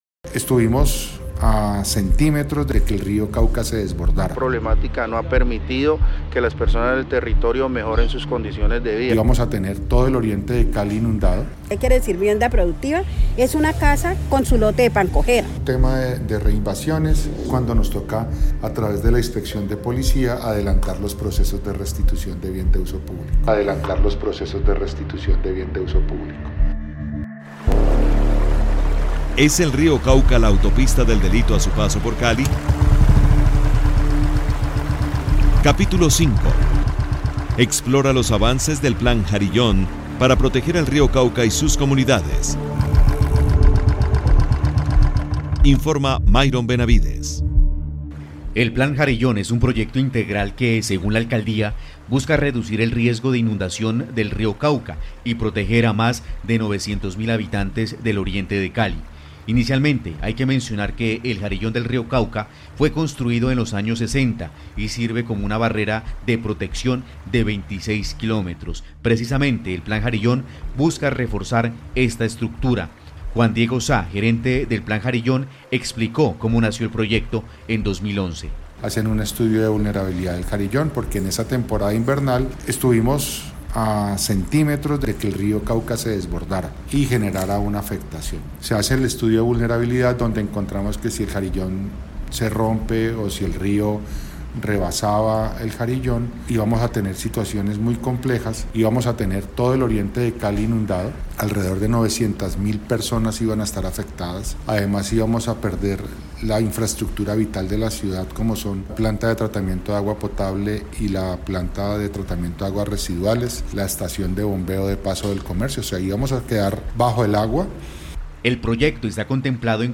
Pasado y presente del río Cauca, con testimonios de la realidad del más importante cauce que cruza a Cali.